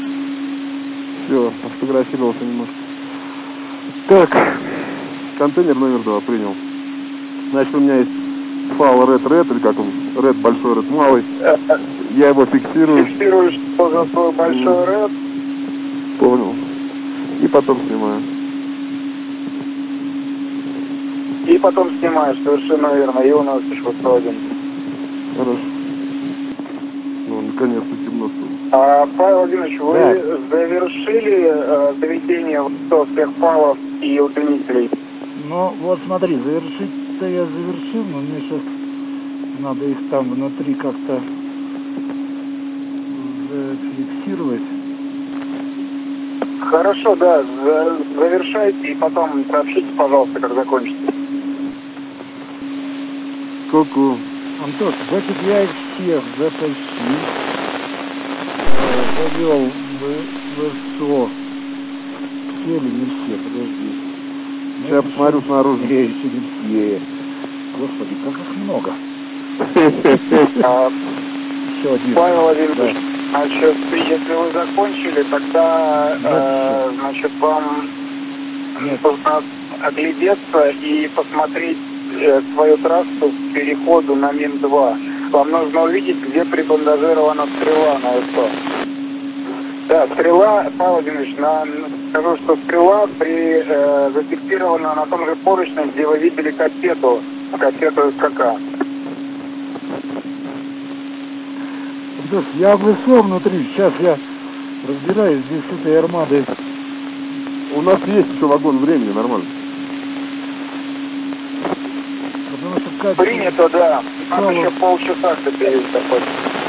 Выход в открытый космос